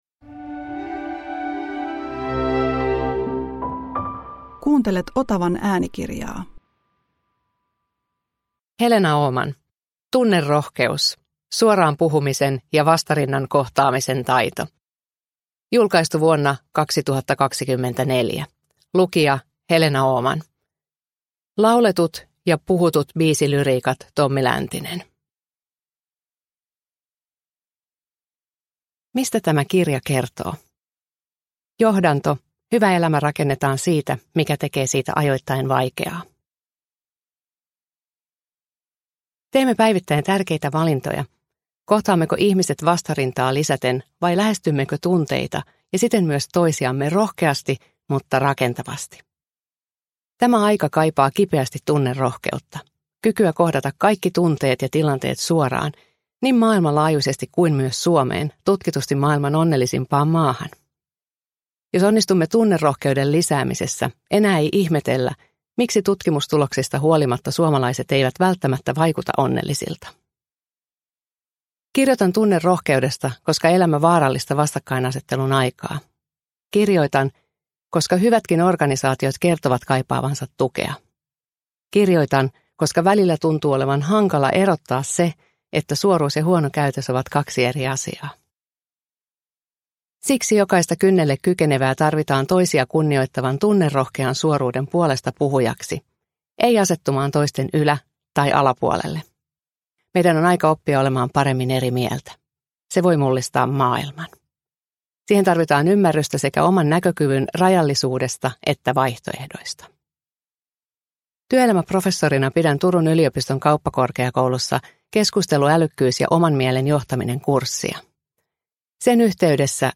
Tunnerohkeus – Ljudbok